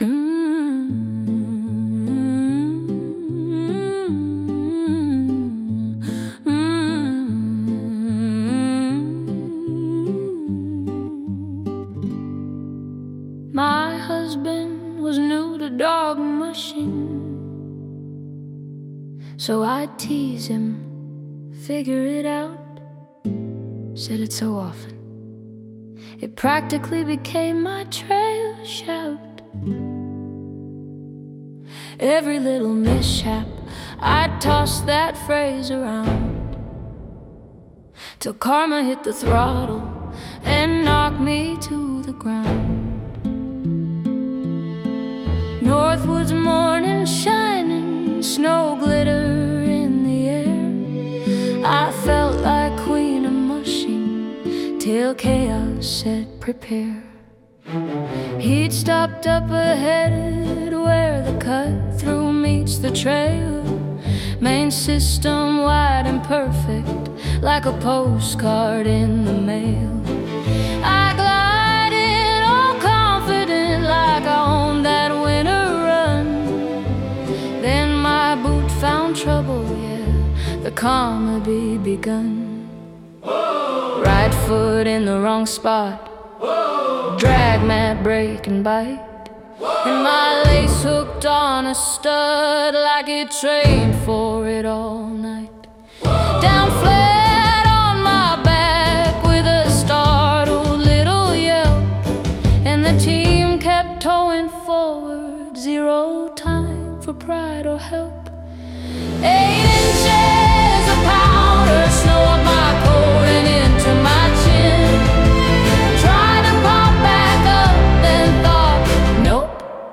We’ve created this special collection of AI-generated songs to further enrich the stories shared here.